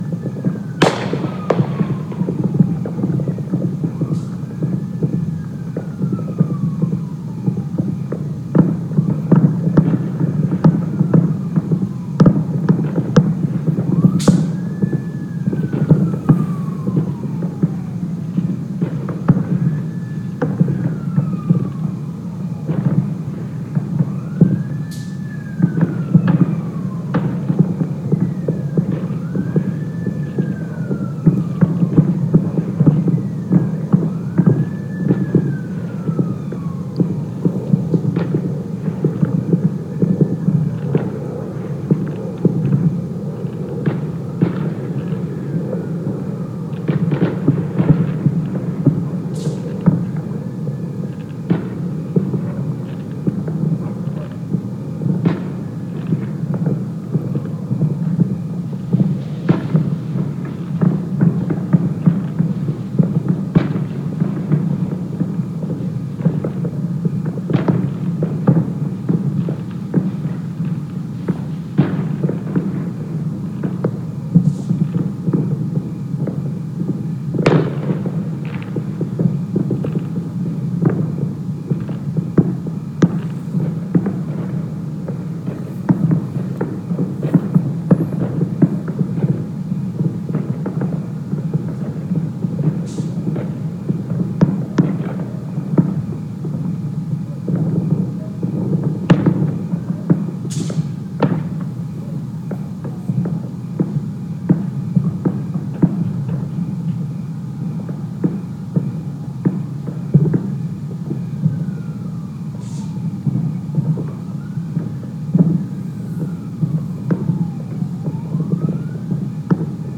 Anyway, despite the fact that we’re in a severe drought, it’s dry as a bone, it’s been hot as hell, and all of these fireworks are illegal as hell – it was a freakin’ war zone out there for two or three hours.
Here’s what a small sample (different sample than the one shown above, to be clear) sounded like.
fireworks_1.mp3